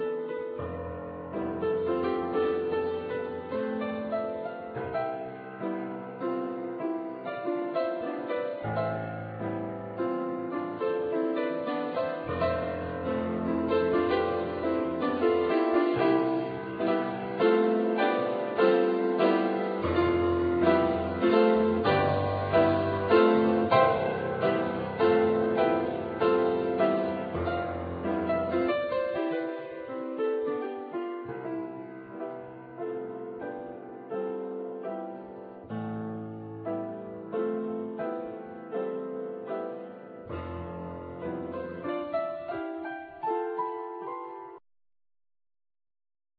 Piano(YAMAHA)